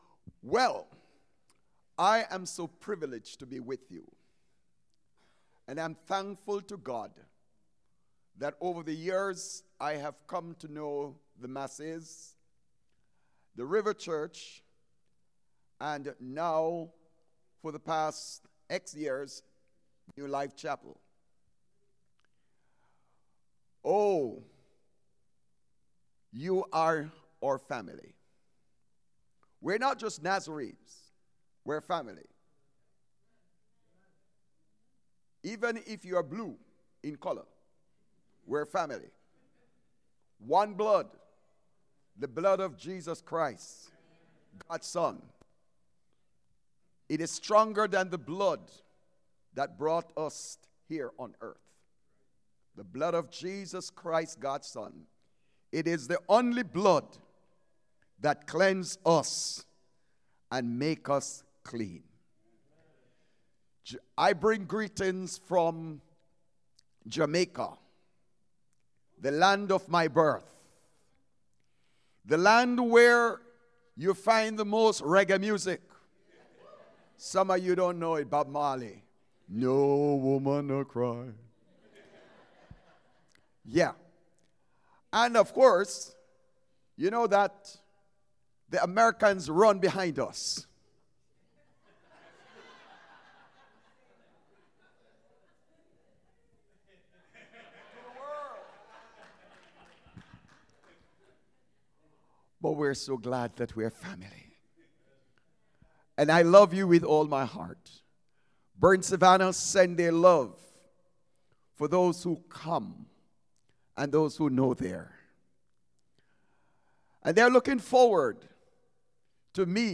Passage: John 21:1-13 Service Type: Sunday Mornings Guest Speaker